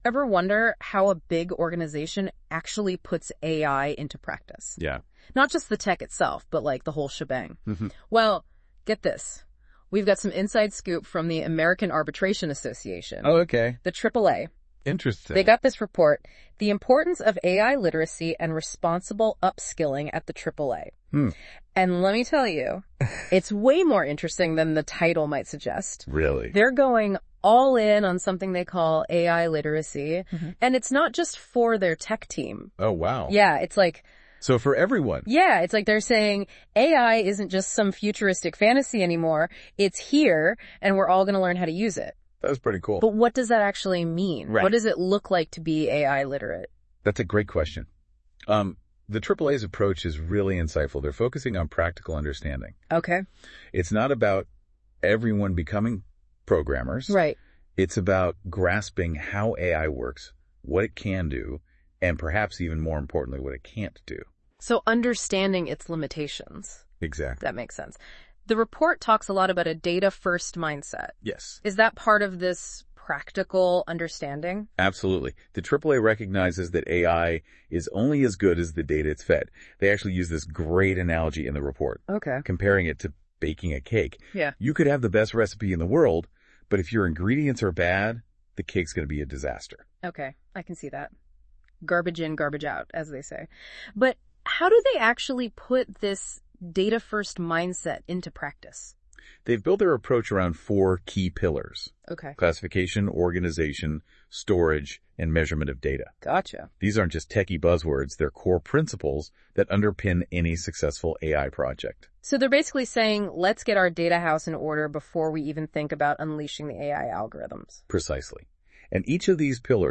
Click here to listen to the AI-generated podcast summary of this article.